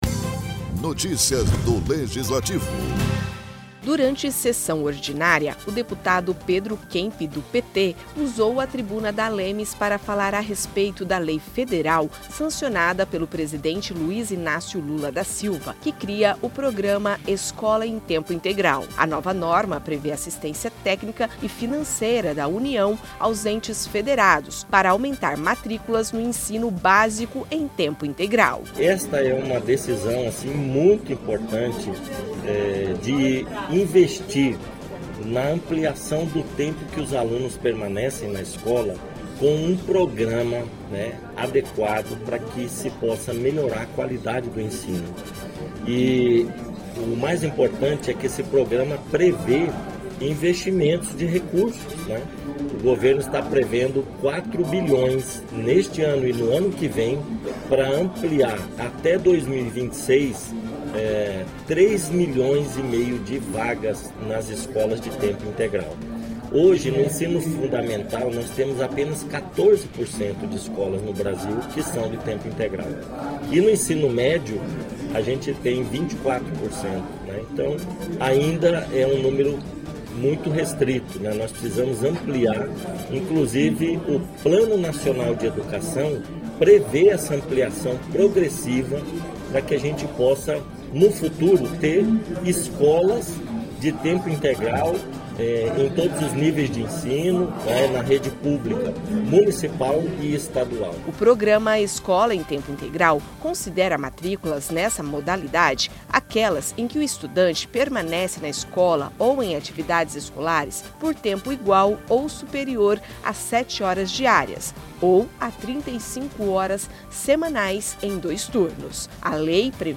Durante sessão ordinária, o deputado Pedro Kemp (PT) usou a tribuna da ALEMS para falar a respeito da Lei Federal, sancionada pelo presidente Luiz Inácio Lula da Silva, que cria o Programa Escola em Tempo Integral. A nova norma prevê assistência técnica e financeira da União aos entes federados para aumentar matrículas no Ensino Básico em tempo integral.